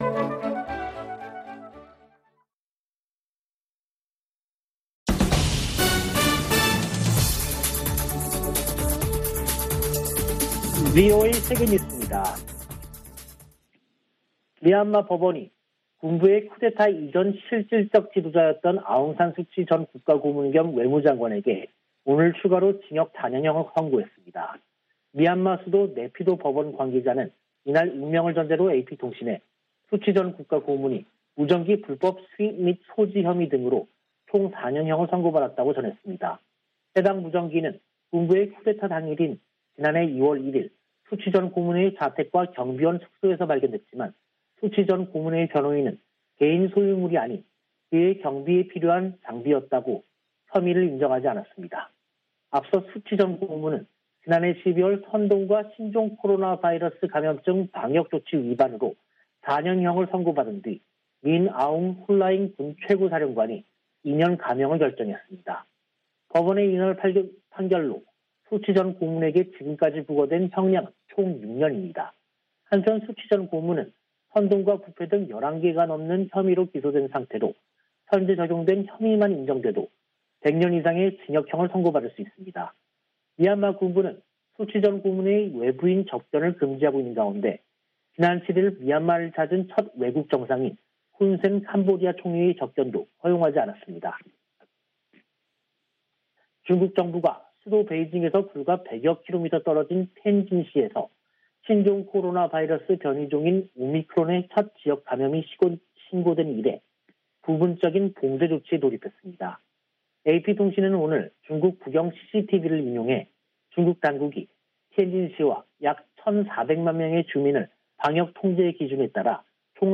VOA 한국어 간판 뉴스 프로그램 '뉴스 투데이', 2022년 1월 10일 3부 방송입니다. 미국 등 5개국이 북한의 미사일 발사에 대한 유엔 안보리 협의를 요청했다고 미 국무부가 밝혔습니다. 북한 극초음속 미사일을 방어하기 위해서는 초기 탐지 능력이 중요하다고 미국의 전문가들은 지적했습니다. 지난해 10월 북한에 들어간 지원 물자가 소독 등을 마치고 정부 배급소에 도착했다고 유엔이 확인했습니다.